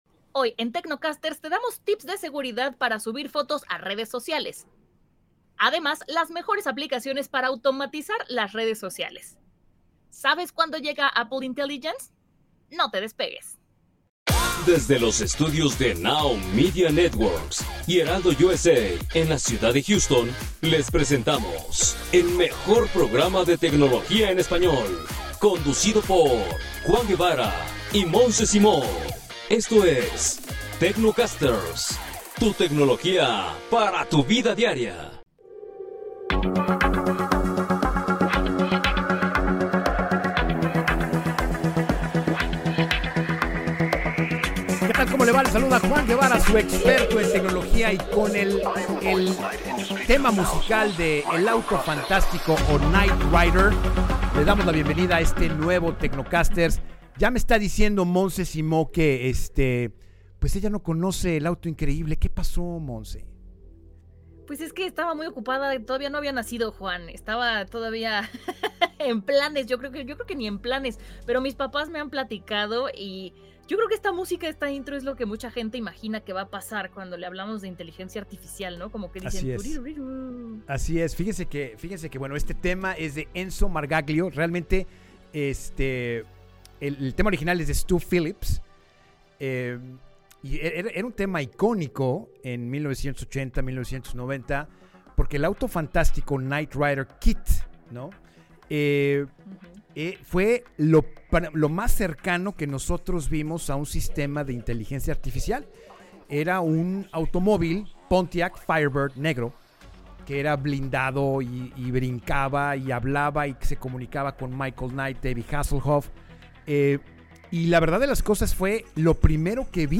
Además, descubre las mejores aplicaciones para automatizar tus redes sociales y todo lo que necesitas saber sobre Apple Intelligence. ¡No te pierdas este episodio desde los estudios de NowMedia Networks en Houston! Escucha a los expertos en tecnología discutir sobre el legendario Auto Fantástico, el poder de la inteligencia artificial, y cómo proteger tu información en el mundo digital.